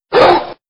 bark5.wav